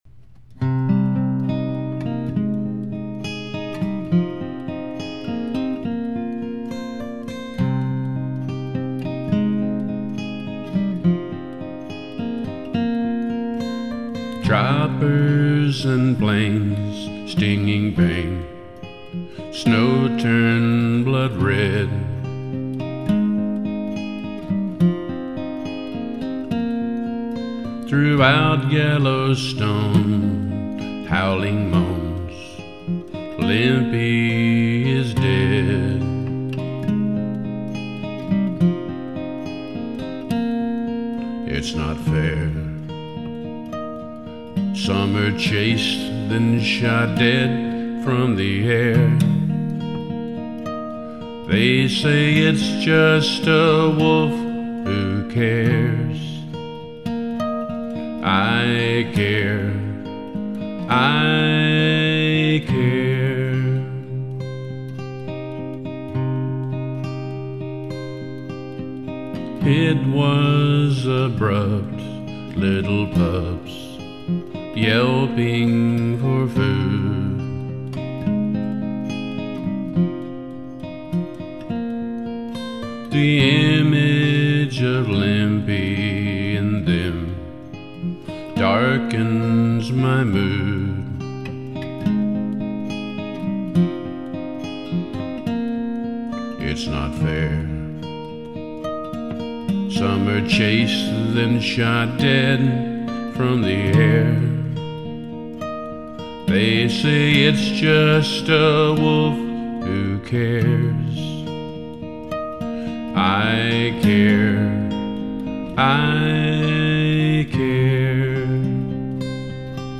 Guitar Ballad Style
NOTE: This is my "working demo," recorded as a "live" songwriter's demo on a portable recorder. It's not a finished studio recording.